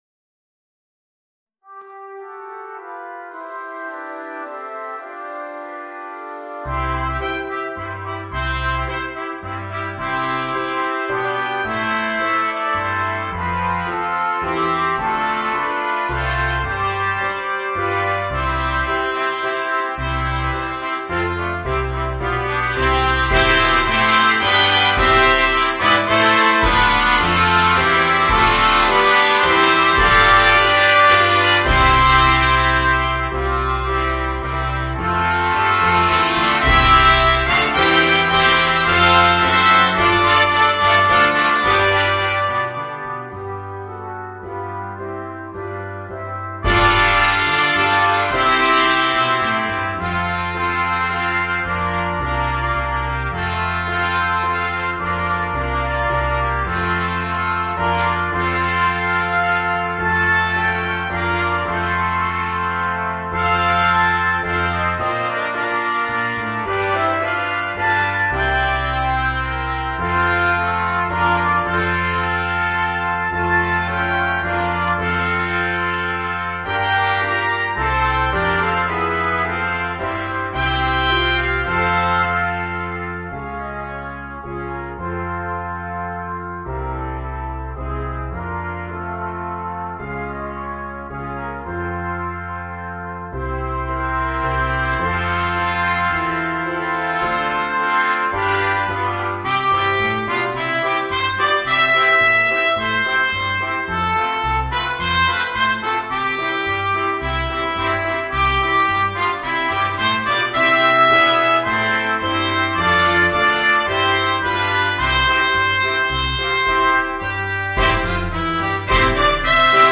Voicing: 5 Trumpets